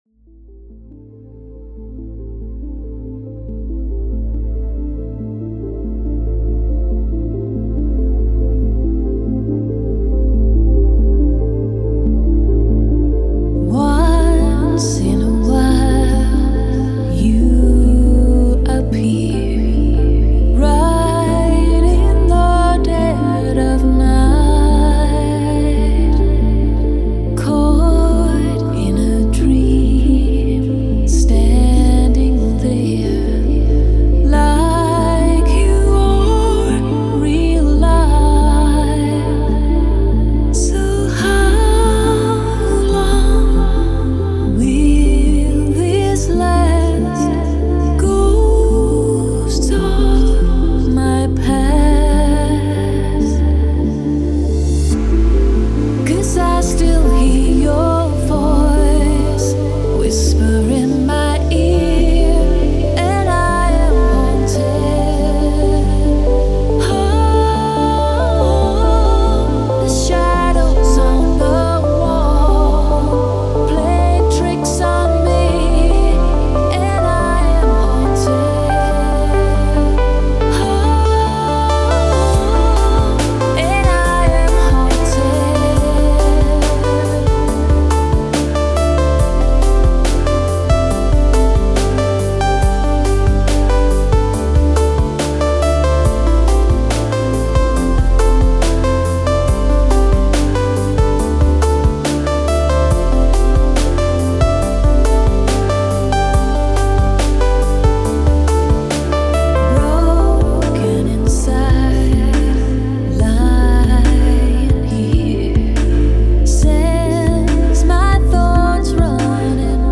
Chill Out Mix
Стиль: Chillout/Lounge / Ambient/Downtempo